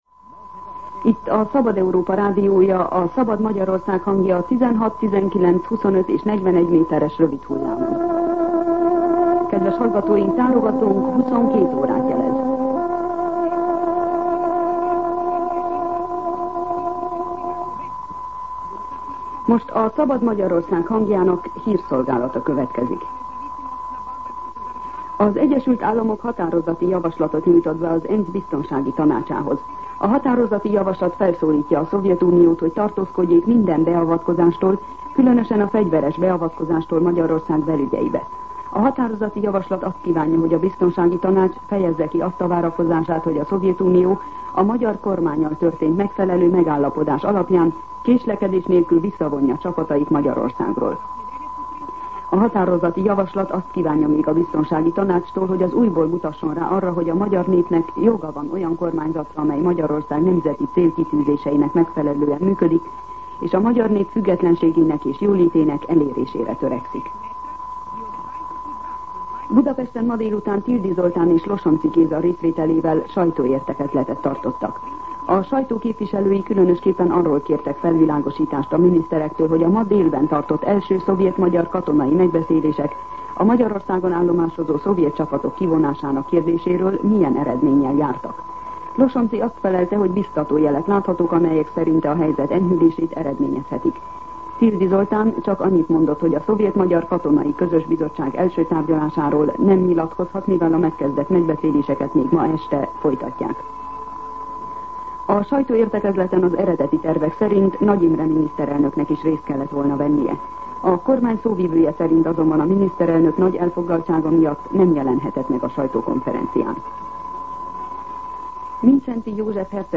22:00 óra. Hírszolgálat